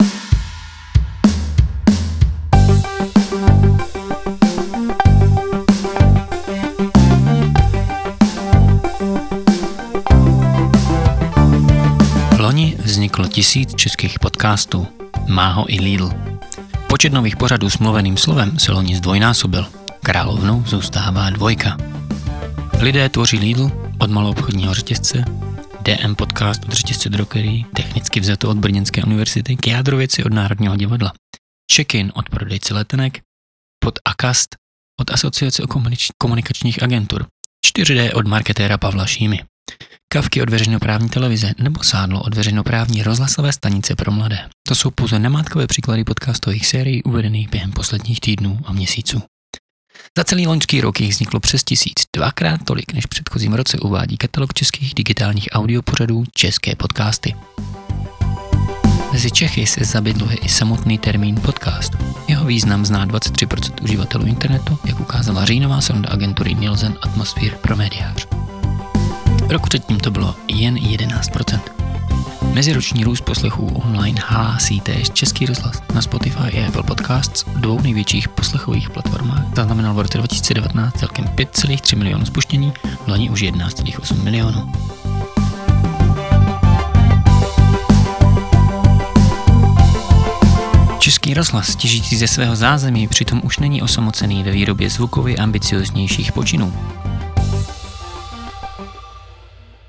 Zajistím potlačení šumů a hluků, výsledný produkt bude dostatečně HLASITÝ, a celkový zážitek z Vašeho pořadu bude velmi příjemný.